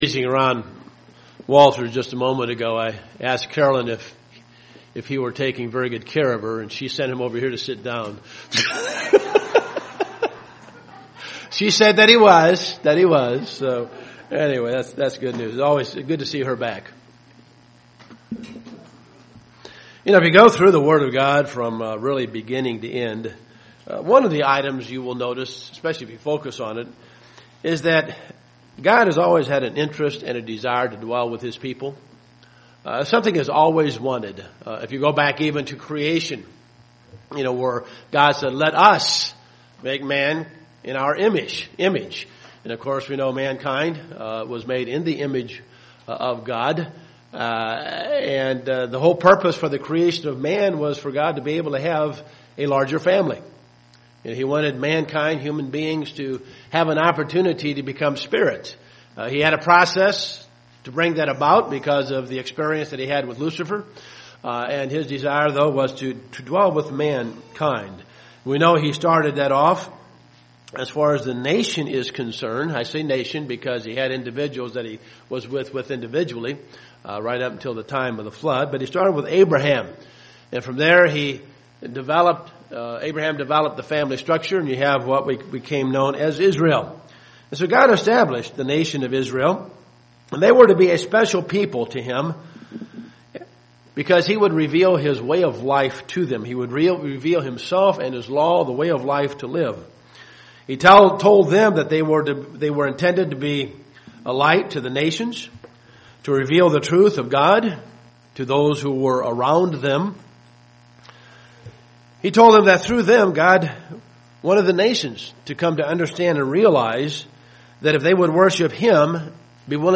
Given in Dayton, OH
Print Gods Glory once departed the Temple but it is one day to return UCG Sermon Studying the bible?